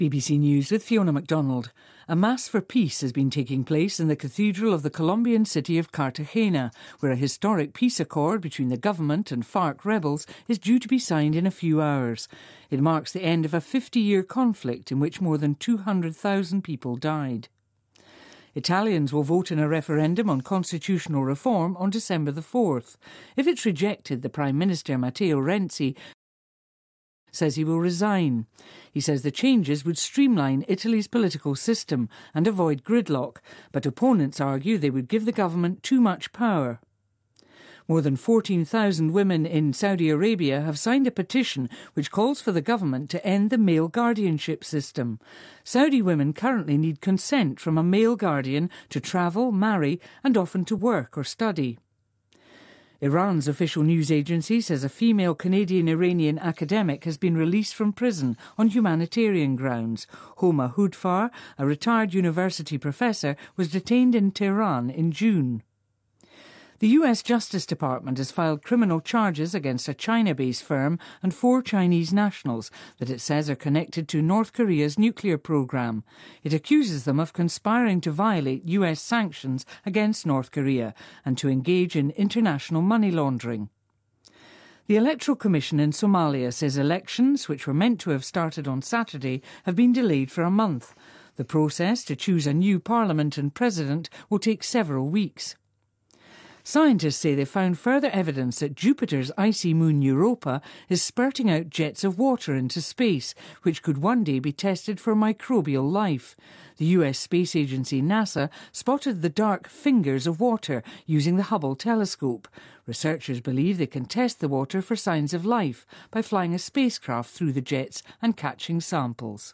BBC news,上万沙特女性请愿撤销监护人法